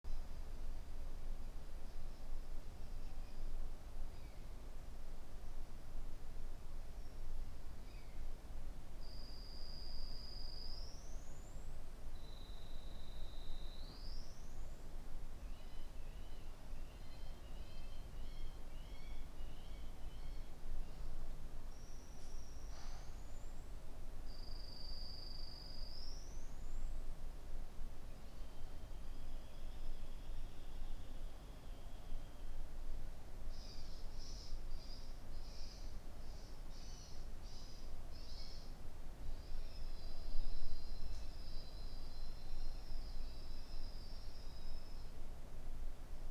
Bruit d'oiseau inc�ssant, repetitif et strident (en plein Paris) - Que faire
Je suis dans le 10eme arrondissement de Paris et ma vie est gach�e � cause d'un bruit de cris d'oiseau.
Le cycle de bruit que je vais mettre en pi�ce joint est toujours le m�me, et ce toute la journ�e/. Le bruit strident penetre chez moi mais je n'arrive � pas � identifier sa provenance.